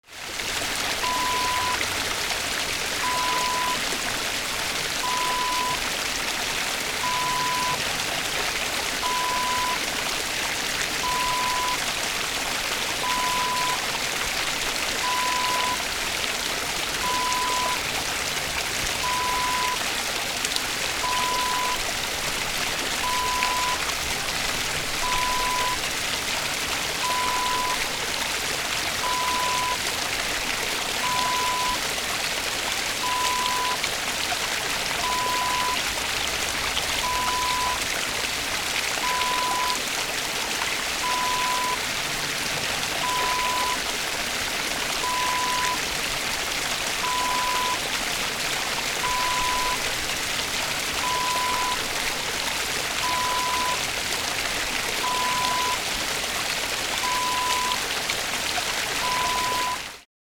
Water Stream (Brook) Wav Sound Effect #3
Description: The sound of a small water stream (brook) in the forest
Properties: 48.000 kHz 16-bit Stereo
A beep sound is embedded in the audio preview file but it is not present in the high resolution downloadable wav file.
Keywords: river, stream, streaming, brook, creek, water, running, rushing, mountain, nature, waterfall, fall, trickle, relaxation
water-stream-small-preview-3.mp3